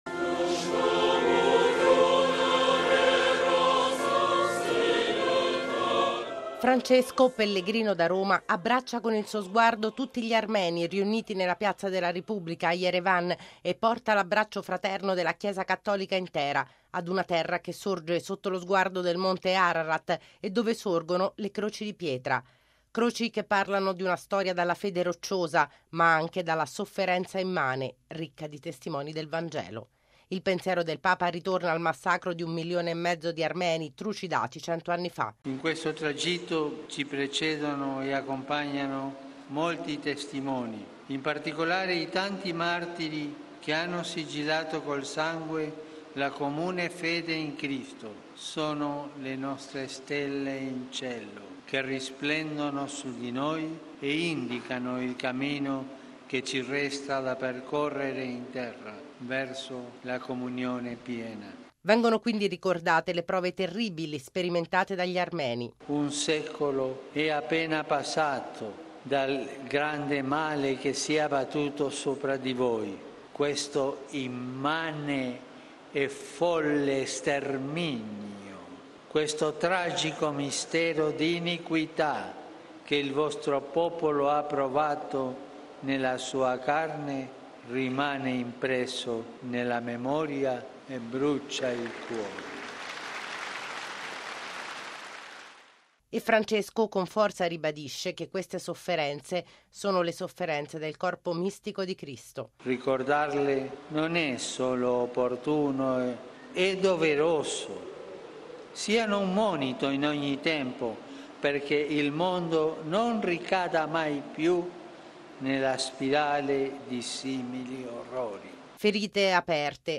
Non si ripetano mai più “orrori” come il “folle sterminio” subito un secolo fa dal popolo armeno. Il Papa ha rinnovato il suo appello a ricordare le ferite del popolo armeno, nel discorso pronunciato nella Piazza della Repubblica di Yerevan, nel corso dell’Incontro ecumenico con il Catholicos Karekin II.